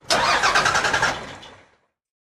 tr_sbus_startstall_01_hpx
Exterior and interior points of view of school bus starts and stalls, and air brake releases. Vehicles, School Bus Bus, School Engine, Motor